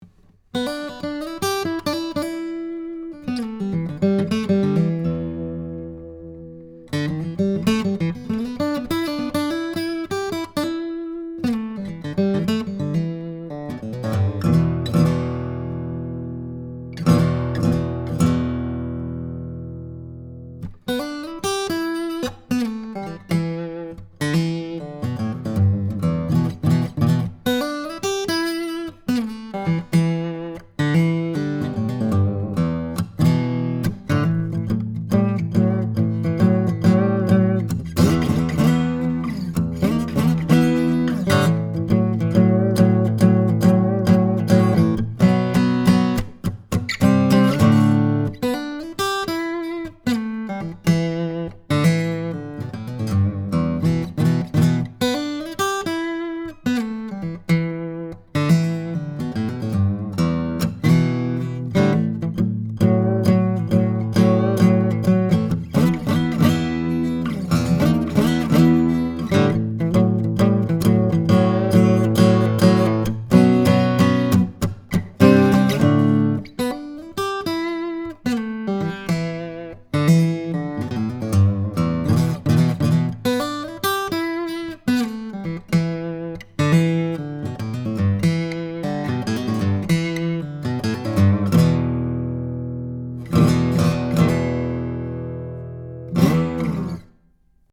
Here are several quick, 1-take MP3 sound files to give you an idea of what to expect. These MP3 files have no compression, EQ or reverb -- just straight signal, tracked with this Rode NT1-A mic into a TAB-Funkenwerk V78M preamp using a Sony PCM DI flash recorder.
SANTA CRUZ OM/PW GUITAR
OMPWNT1aV78D1Boogie.mp3